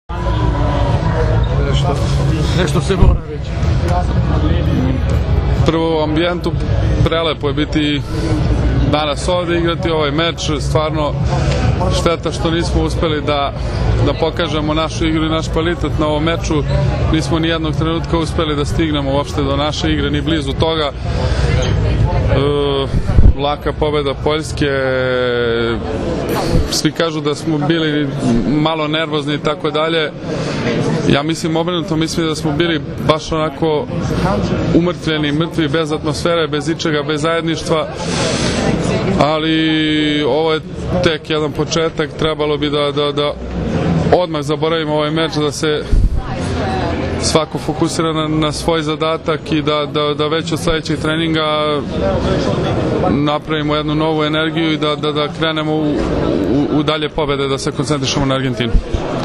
IZJAVA NIKOLE ROSIĆA